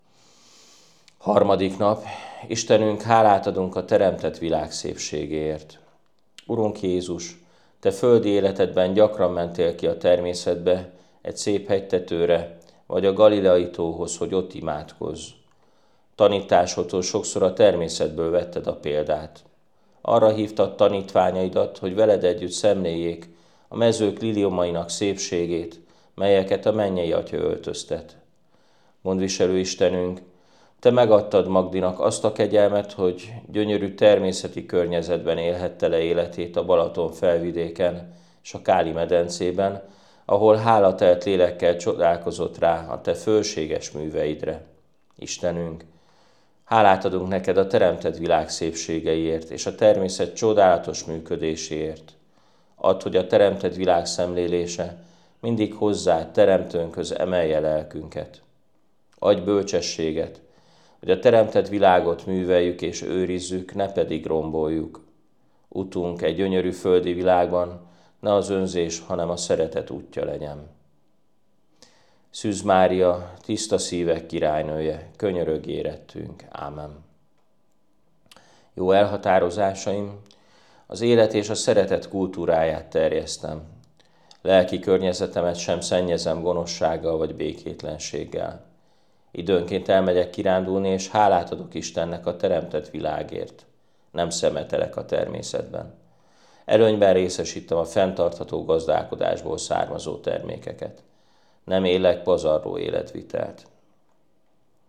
felolvasásában: